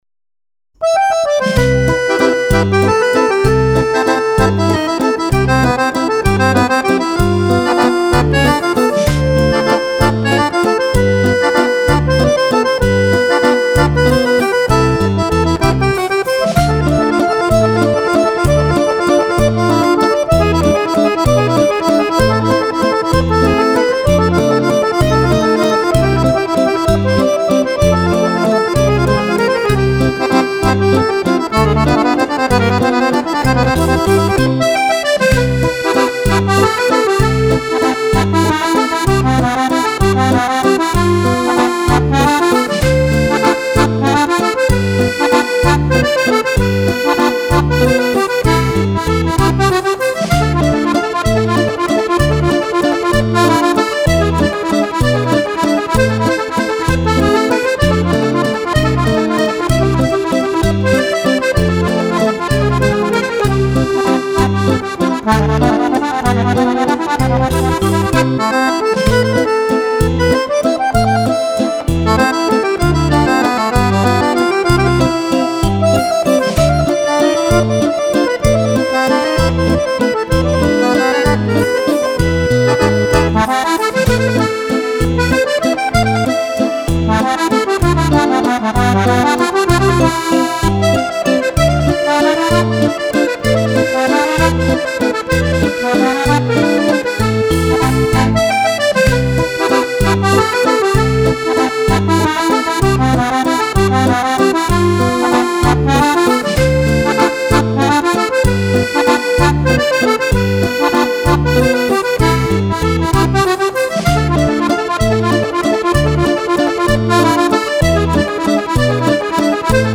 Valzer Musette per Fisarmonica